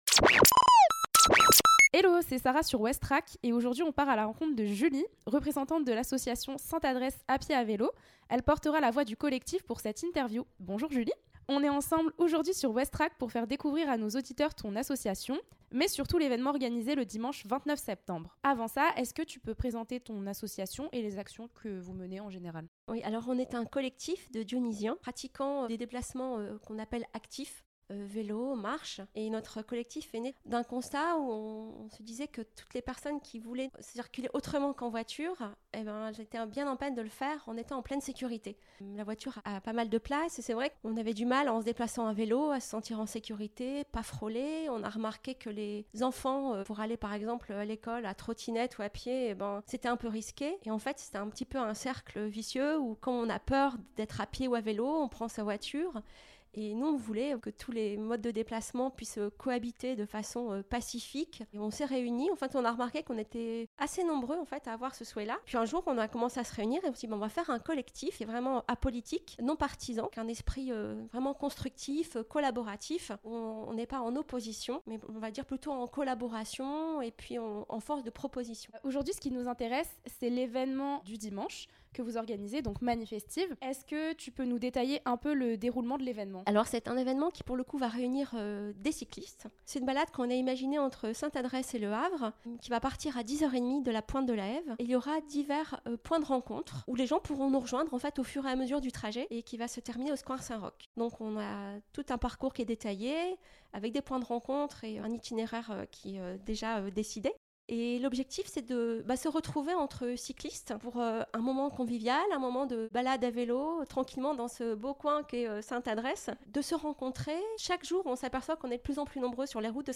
A travers cette courte interview, vous découvrirez l'événement "Balade mani-festive" organisé le dimanche 29 septembre 2024, les enjeux de cette action et d'autres informations utiles sur ce collectif.